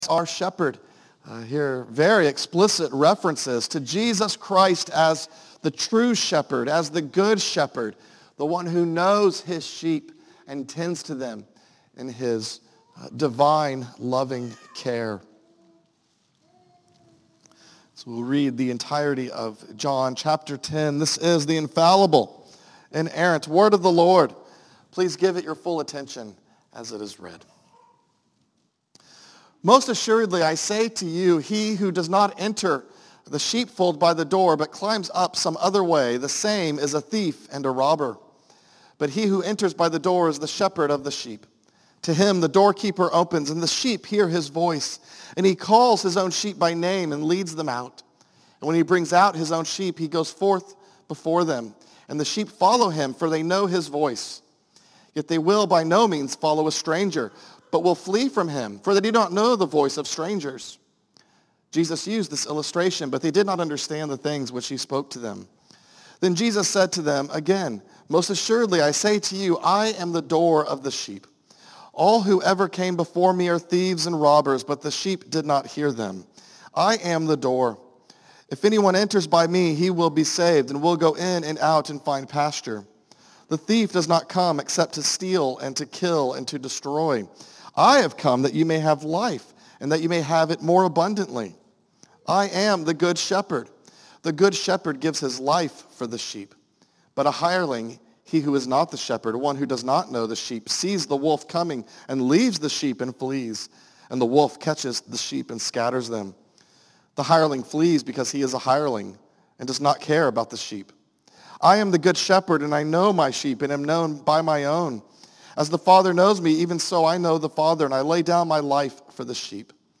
A message from the series "Psalms."